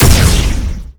plasma_fire.wav